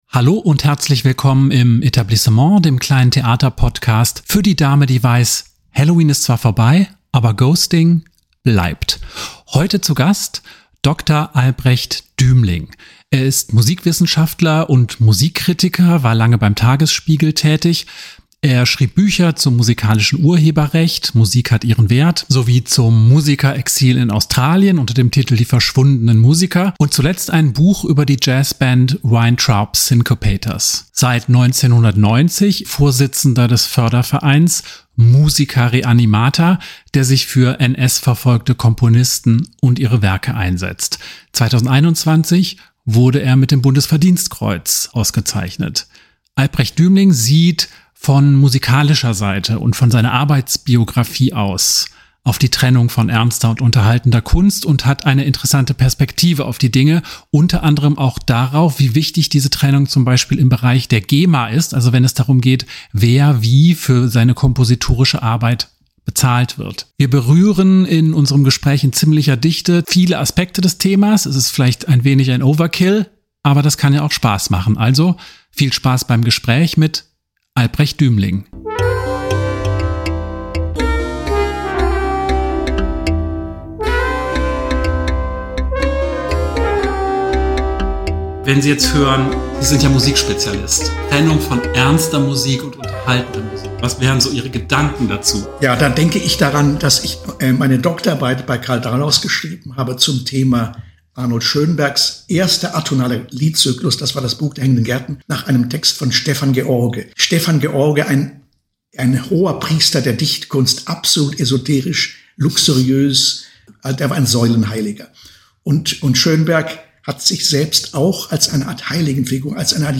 Ein Gespräch mit dem Musikwissenschaftler